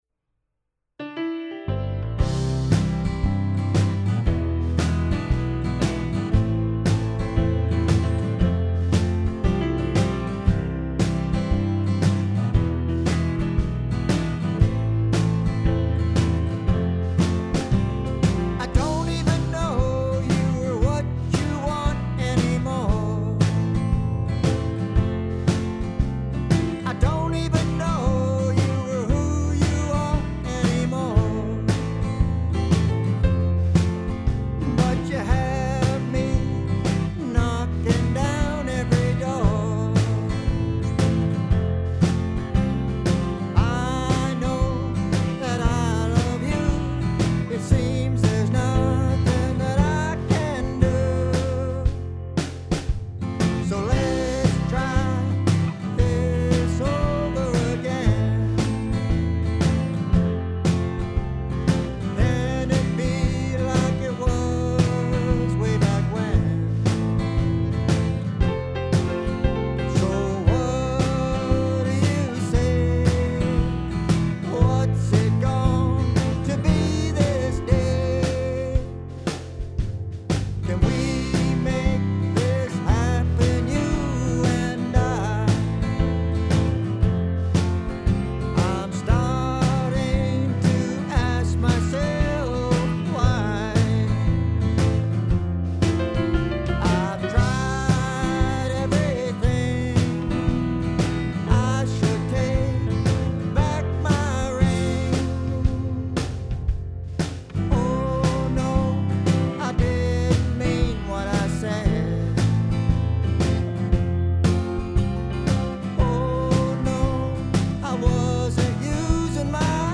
One was a country song with this chorus:
country/western song